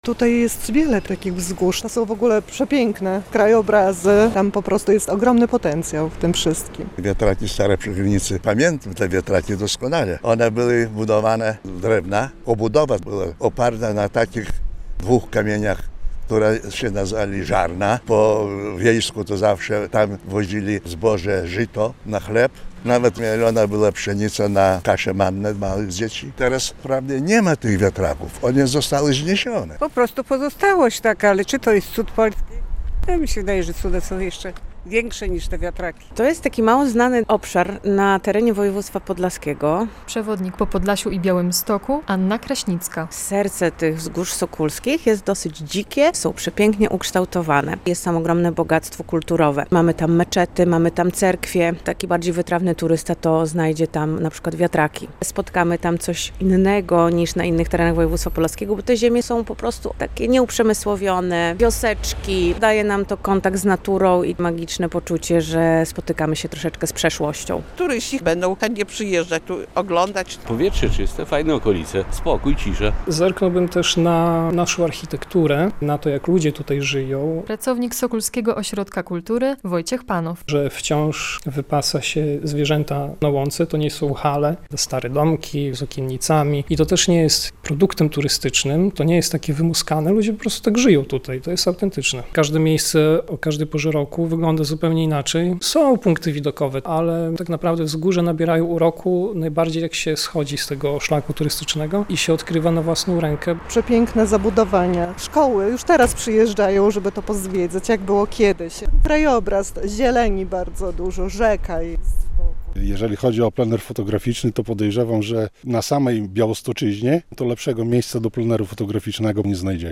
Słuchaj: Zapytaliśmy mieszkańców Sokółki co wiedzą o Wzgórzach Sokólskich | Pobierz plik.